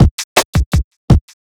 HP082BEAT2-L.wav